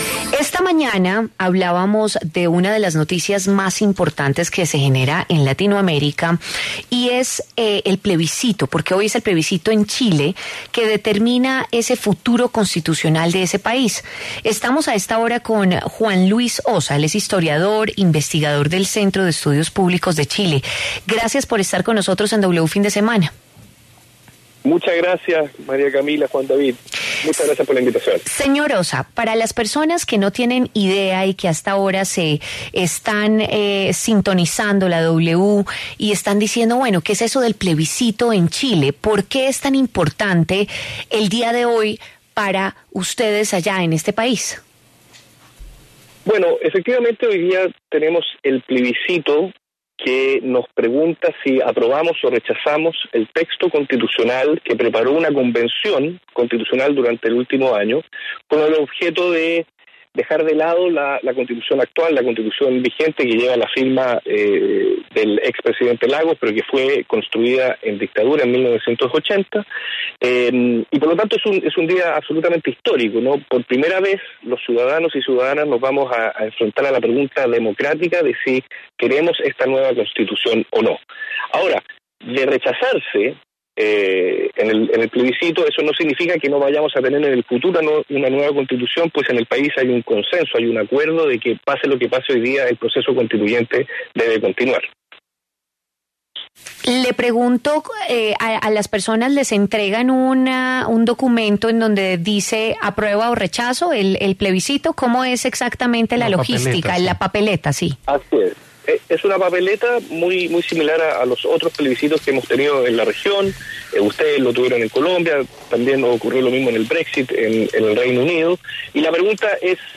pasó por los micrófonos de W Fin de Semana para hablar sobre el plebiscito constitucional que se desarrolla este domingo en el país austral.